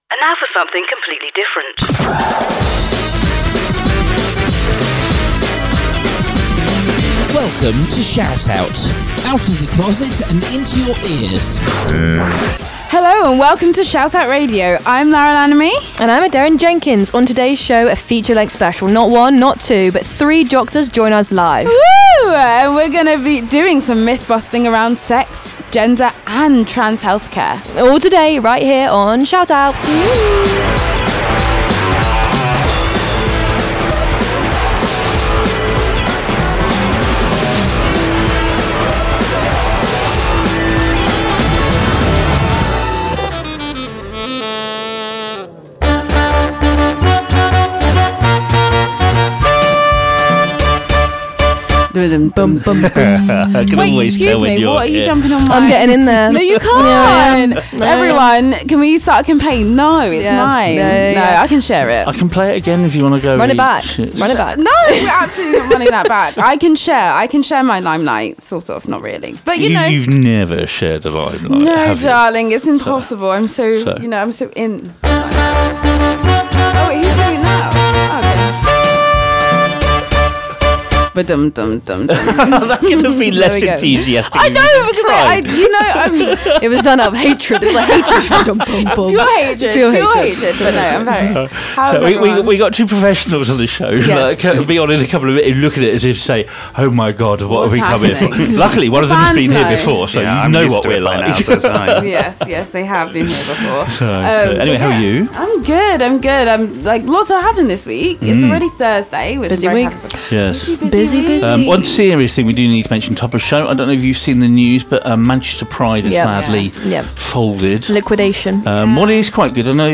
we chat to 3 doctors to bust the myths